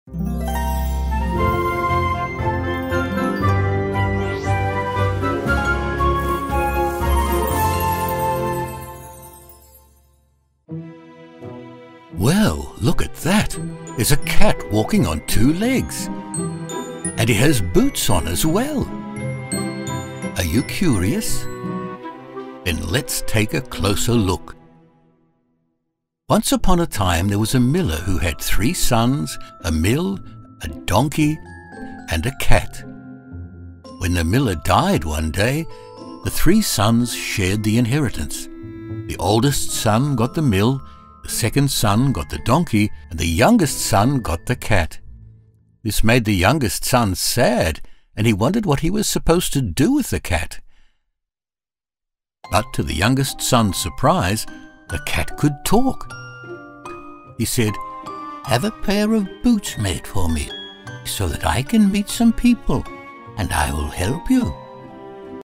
Animation
Rode NT-1A Microphone, Focusrite interface.
BaritoneDeepLow